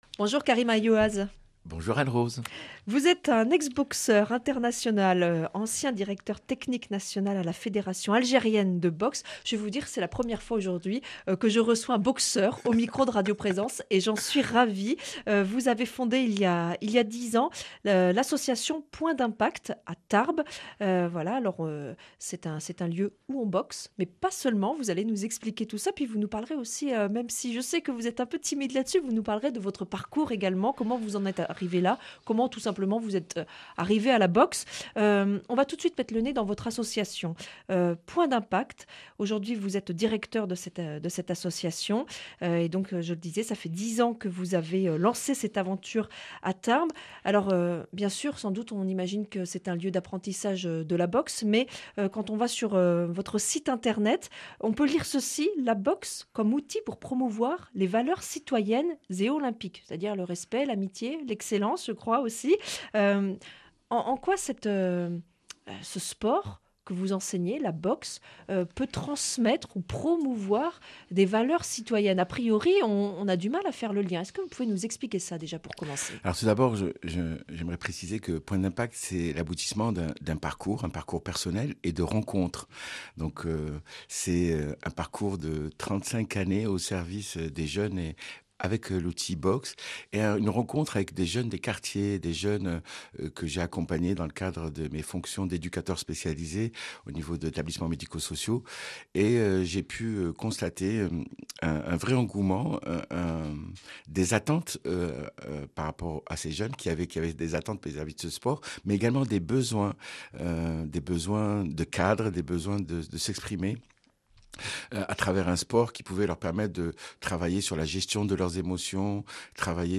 Accueil \ Emissions \ Information \ Locale \ Interview et reportage \ Ancien champion de boxe